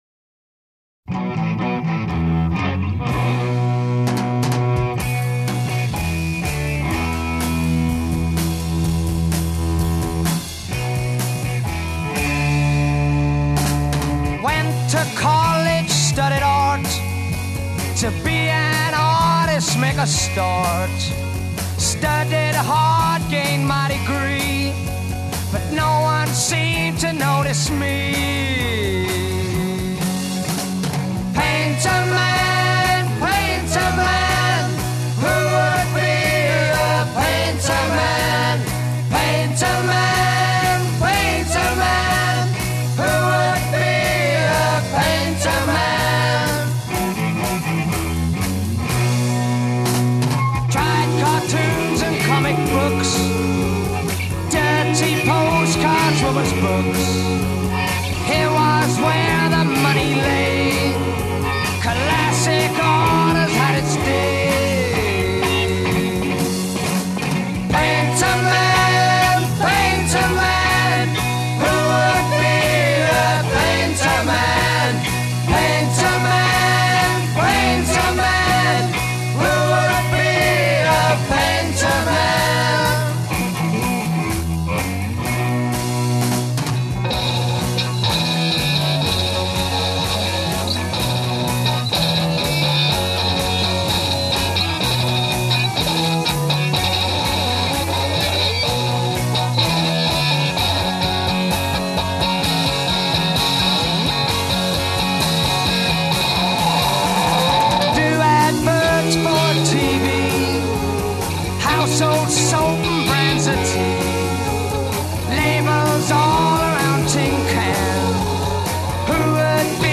Introduction   Guitar solo features bowing.
A Verse   Vocal solo over ensemble.
A Verse   As above with sustaining choral chords.
A Verse   Bowed guitar solo (extended ending).
B Refrain   As in refrains above without drums and bass e
Psychedelic Pop